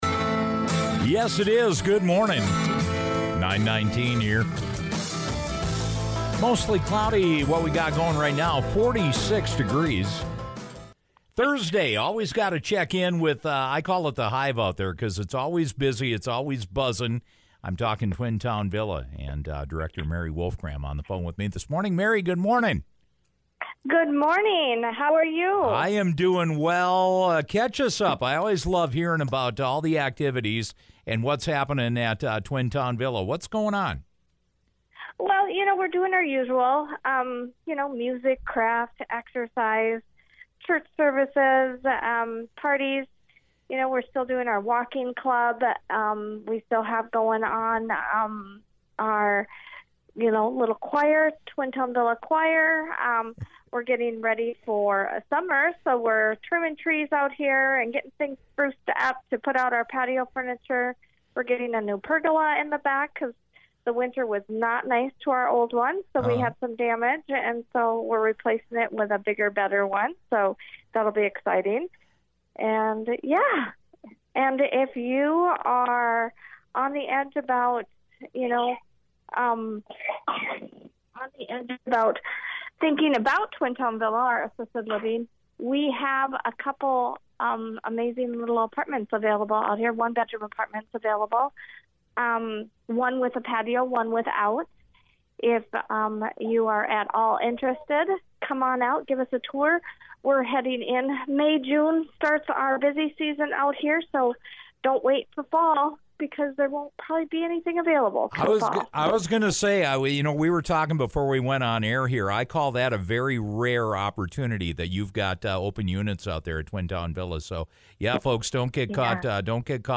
This is a rare chance to become a part of the top tier in assisted living. Listen to our conversation below.